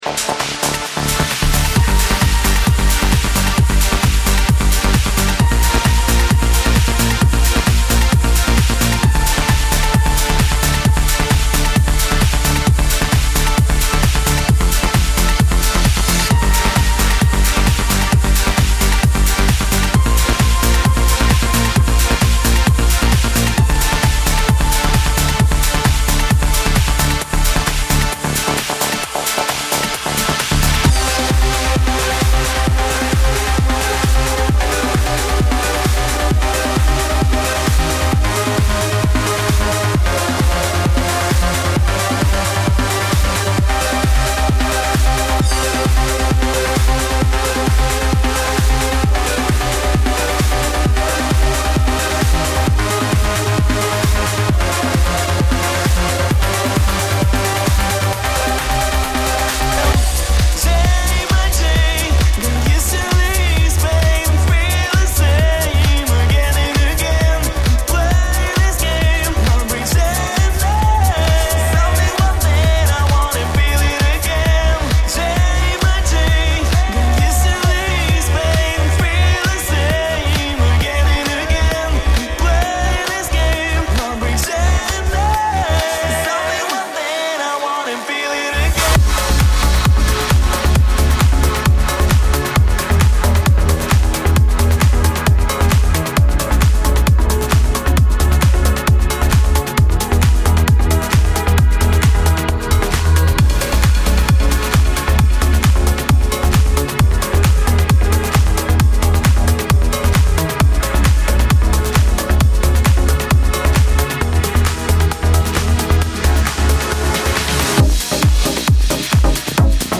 Главная » Файлы » Trance , Disco, Club , D&J